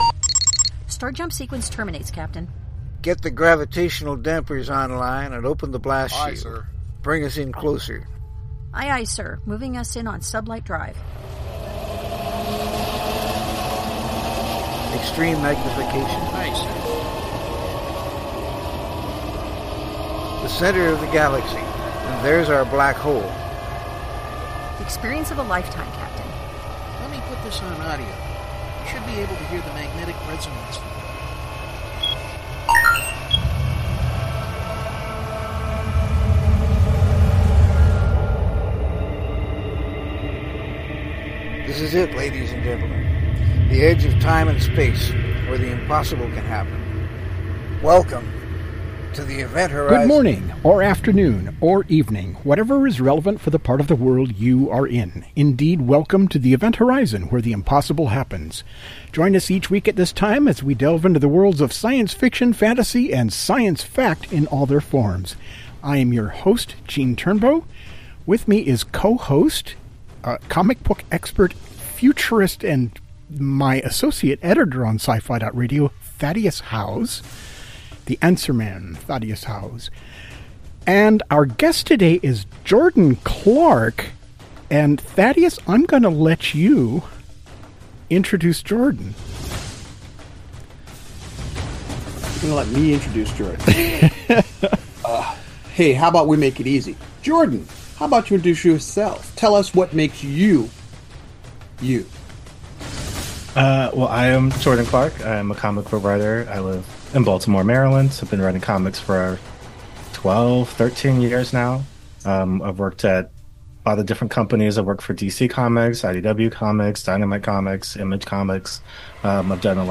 interview the writer behind this remarkable graphic novel